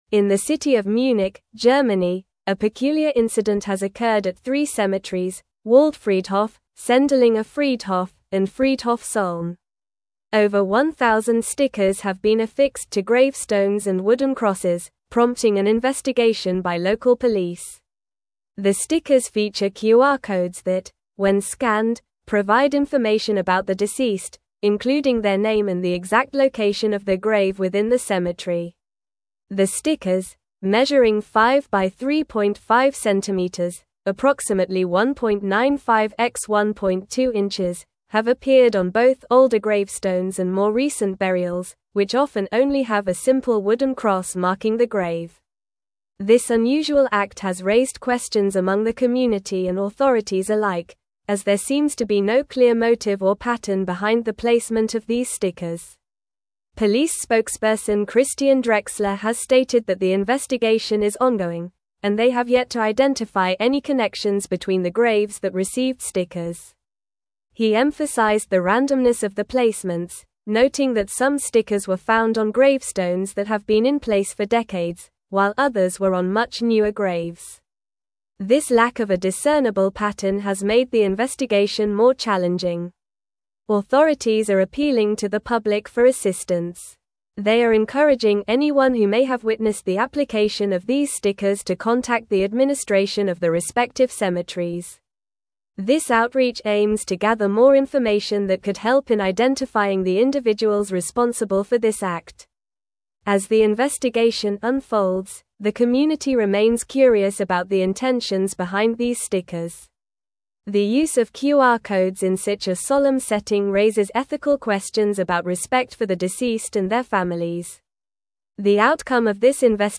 Normal
English-Newsroom-Advanced-NORMAL-Reading-QR-Code-Stickers-Appear-on-Gravestones-in-Munich.mp3